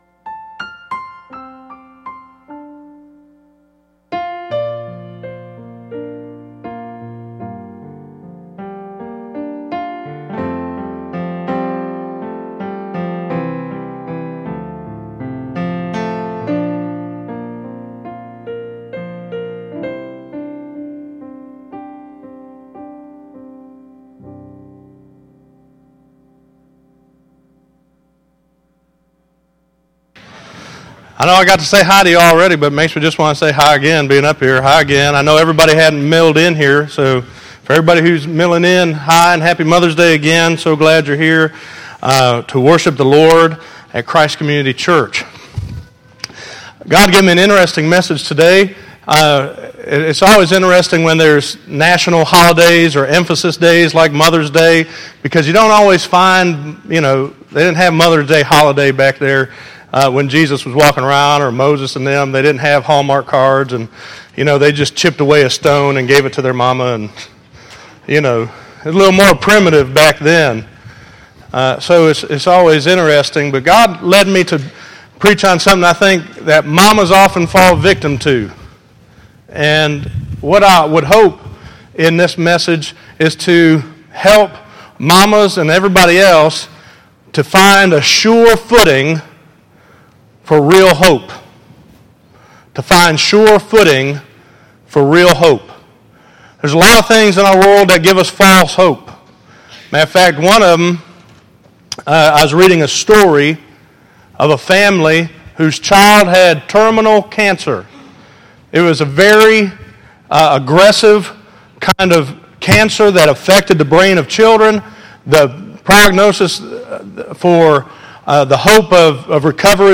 Listen to Defeating False Hope - 05_11_2014_Sermon.mp3